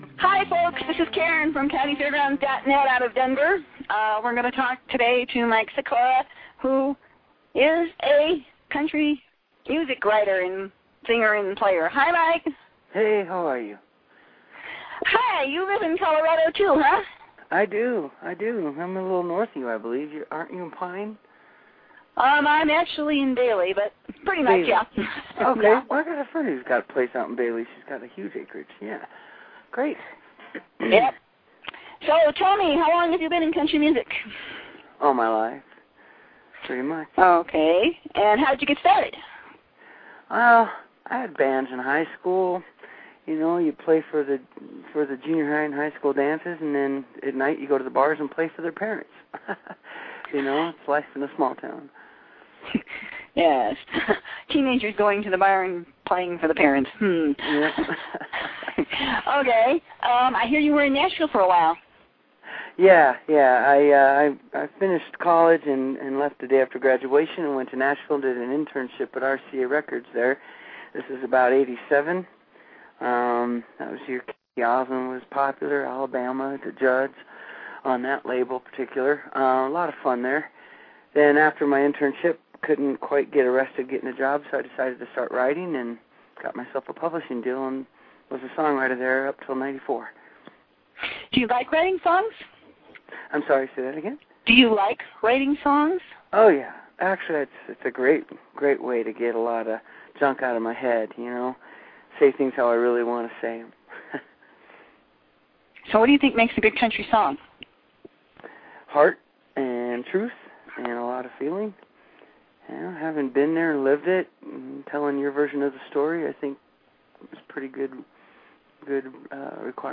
perfect wedding song for 2009
” which captured his gritty classic country style.